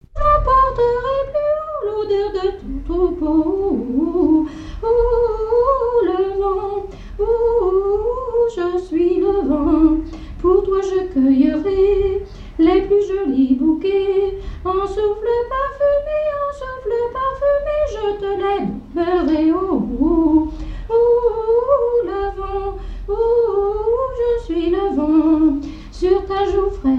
Genre strophique
airs de danses et chansons traditionnelles
Pièce musicale inédite